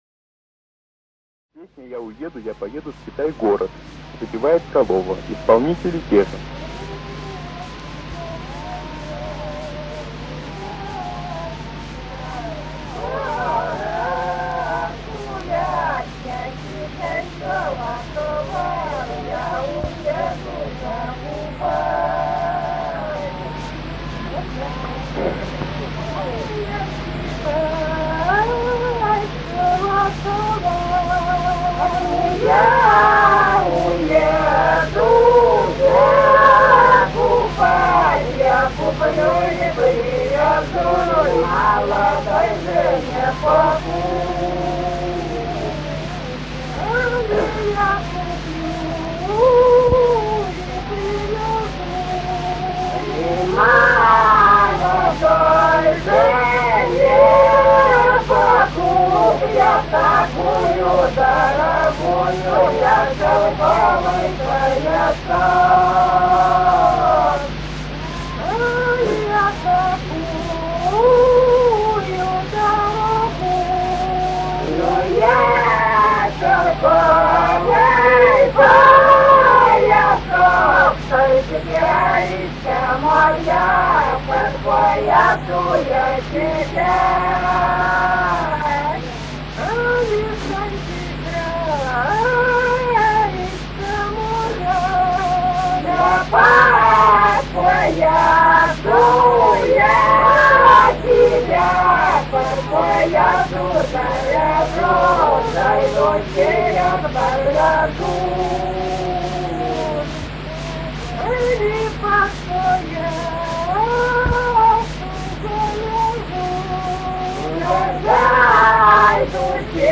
Русские народные песни Владимирской области 36. Я уеду, я поеду во Китай-город гулять (хороводная) с. Михали Суздальского района Владимирской области.